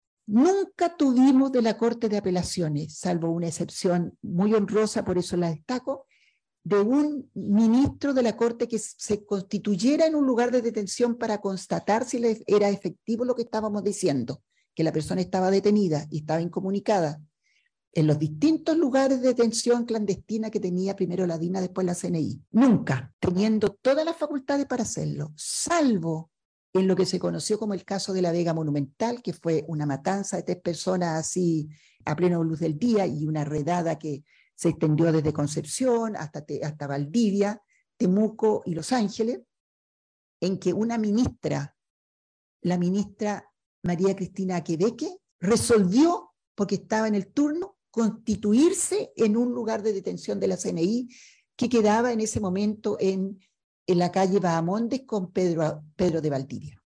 Martita Wörner Tapia, quien trabajó en esta institución como directora ejecutiva de la Pastoral de DD.HH del Arzobispado de Concepción, participó en un seminario organizado por el Departamento de Historia y Filosofía del Derecho UdeC, en el que abordó el rol de los abogados y abogadas de la Vicaría, durante la dictadura chilena.